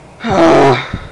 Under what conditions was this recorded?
Download a high-quality frustrated sound effect.